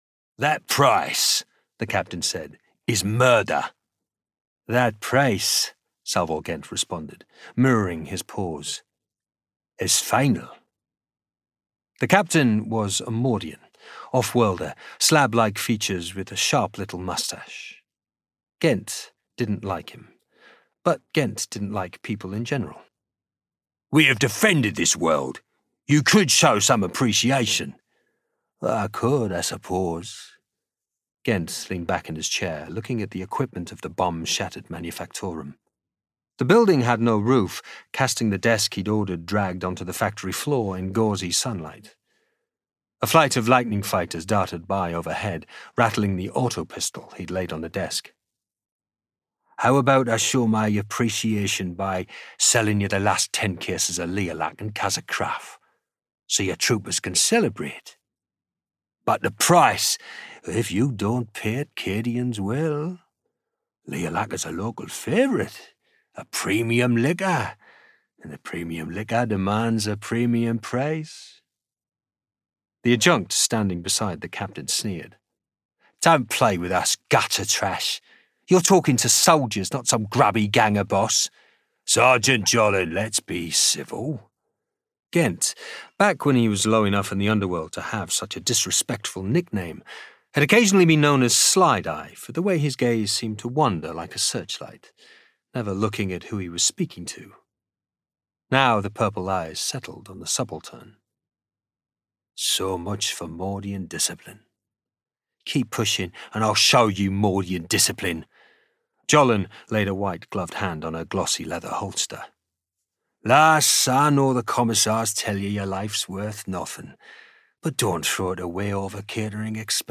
Native Accent: Teesside Characteristics: Confident and Versatile Age: 40-50 View on spotlight Commercial Character Audiobook - Ironopolis Audiobook - Fall of Cadia